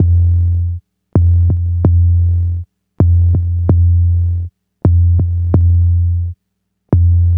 NRG 4 On The Floor 022.wav